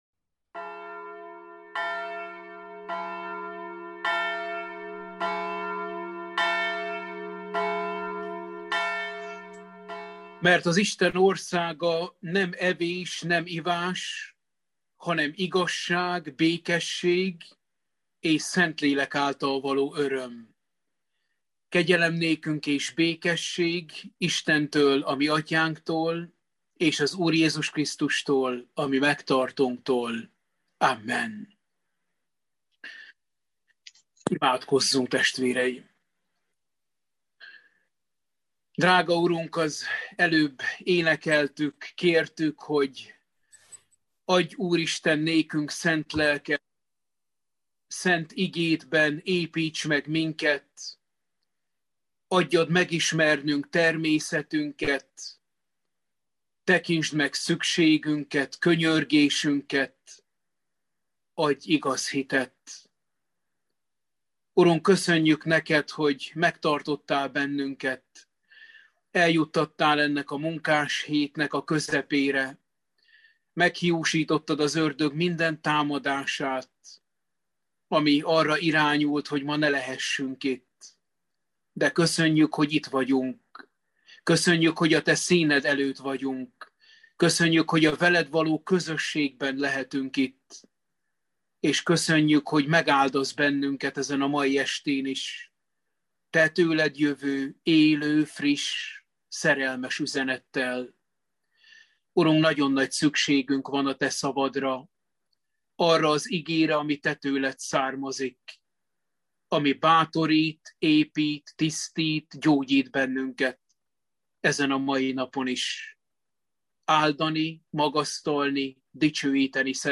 Római levél – Bibliaóra 32